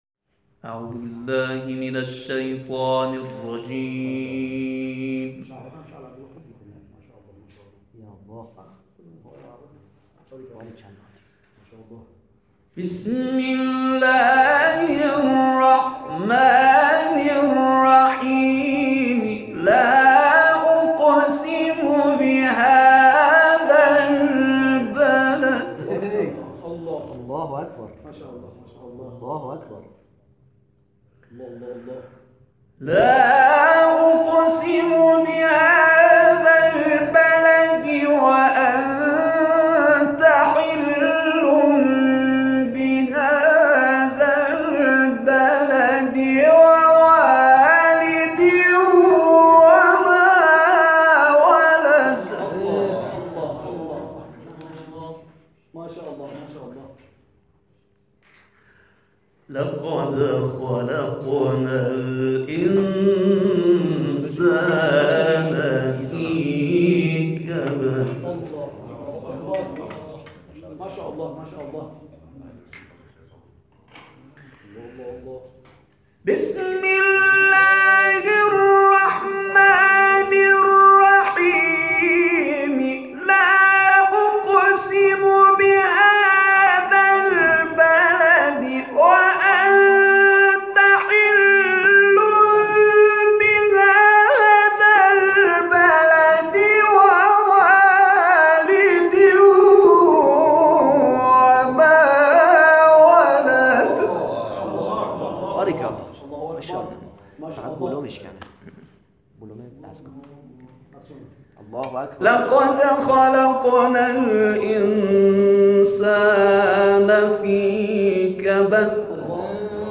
در ادامه تلاوت‌های این جلسه ارائه می‌شود.